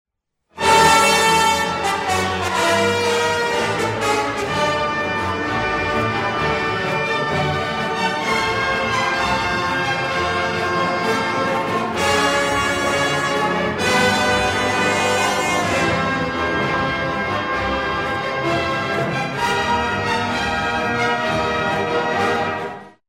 Key: G Minor